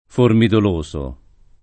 DOP: Dizionario di Ortografia e Pronunzia della lingua italiana
formidoloso [ formidol 1S o ] agg.